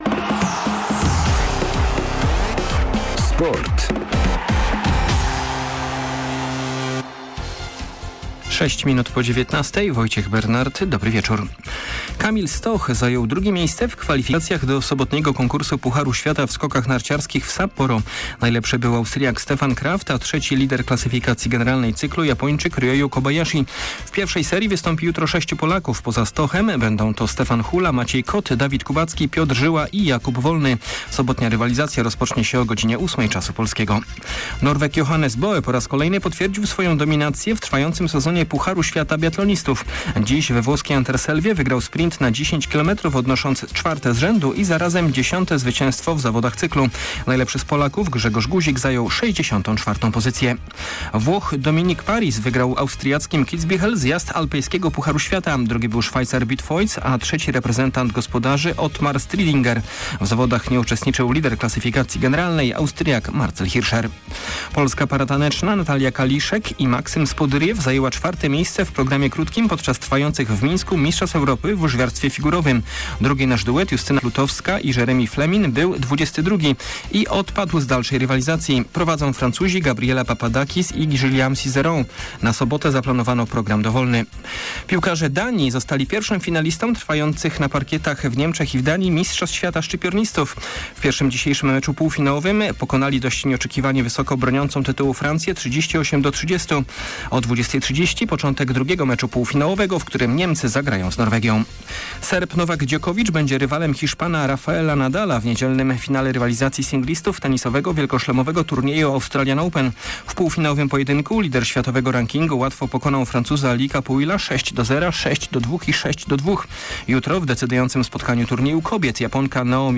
25.01. SERWIS SPORTOWY GODZ. 19:05